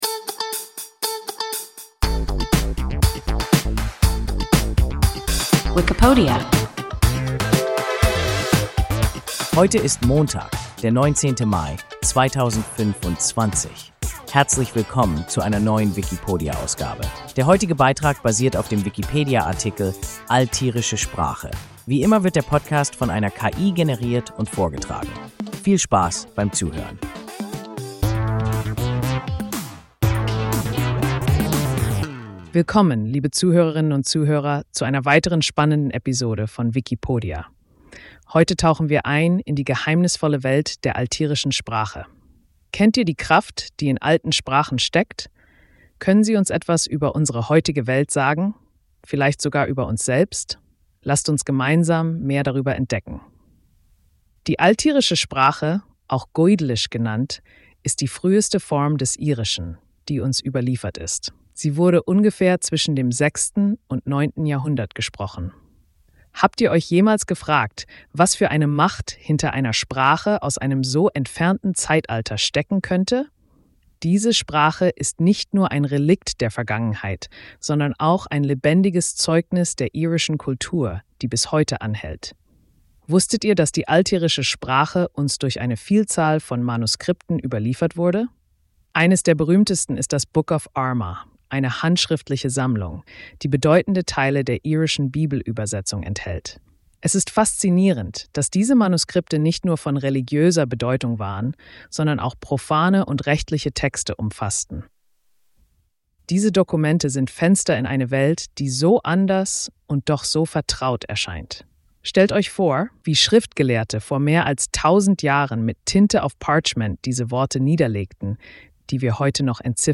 Altirische Sprache – WIKIPODIA – ein KI Podcast